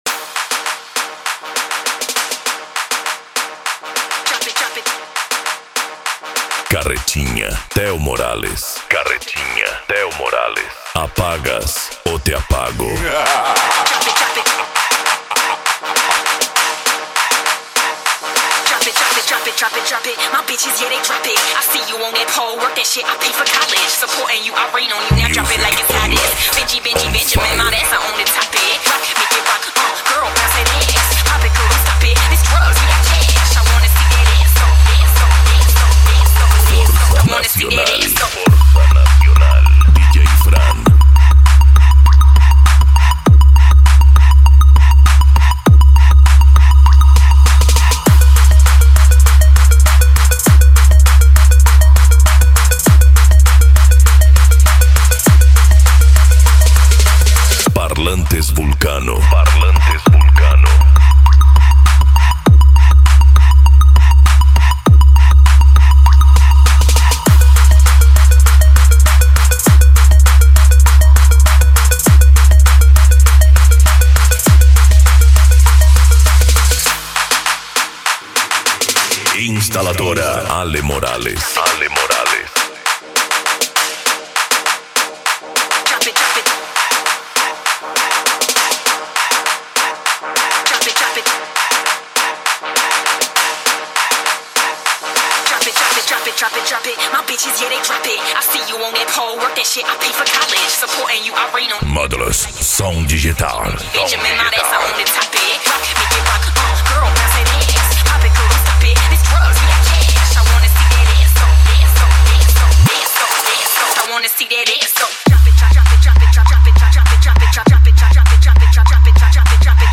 Psy Trance
Remix